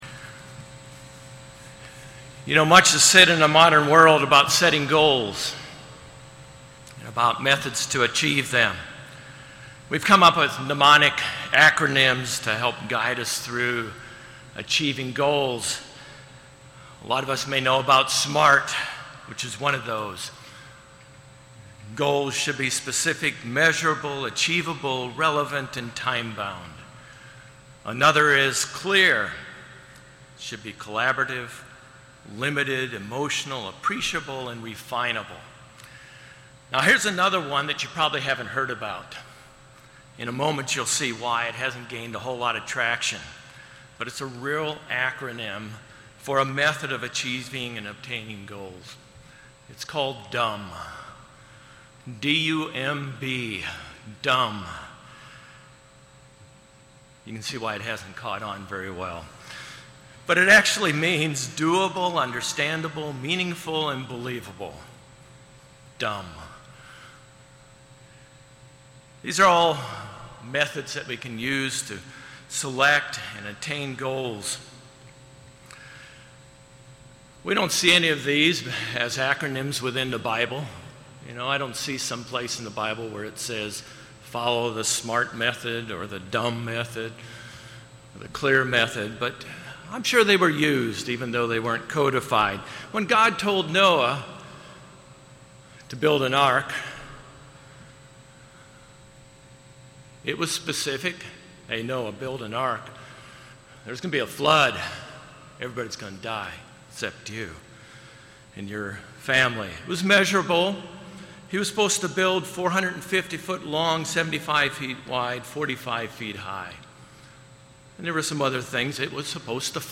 This sermon was given at the Daytona Beach, Florida 2021 Feast site.